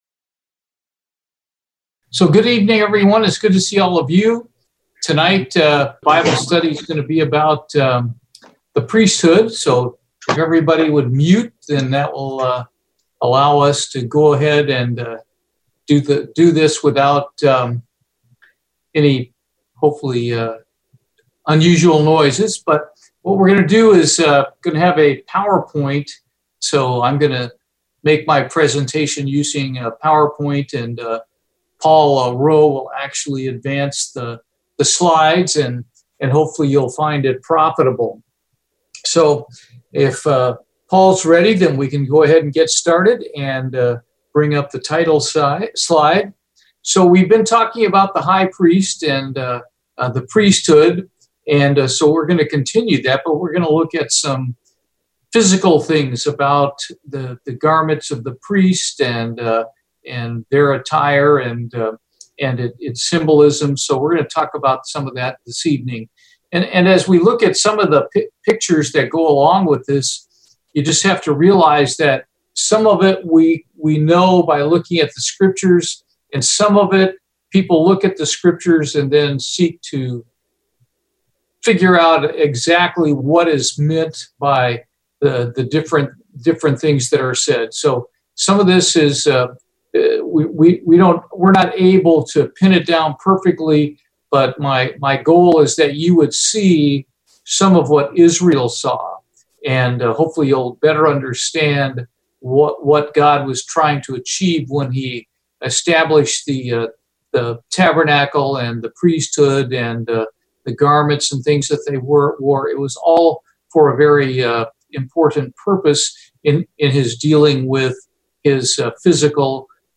Given in Houston, TX